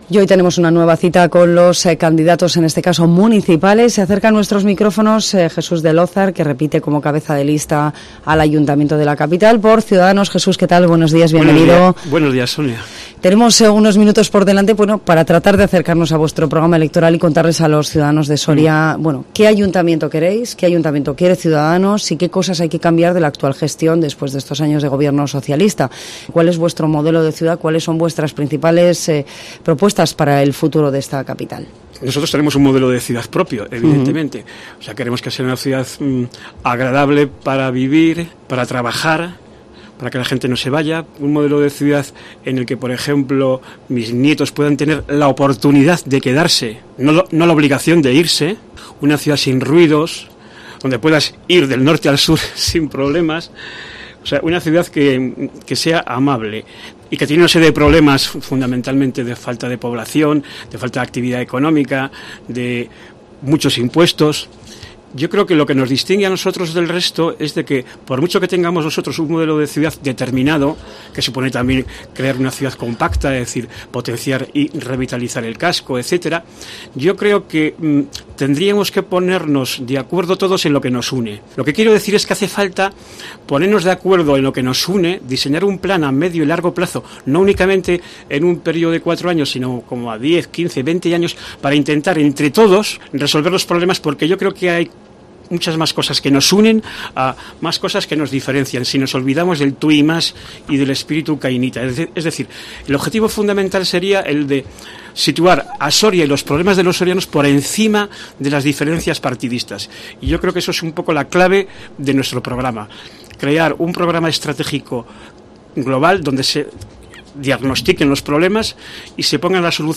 Entrevista Ciudadanos elecciones municipales Soria